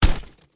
Concrete Impacts
ConcreteHit07.wav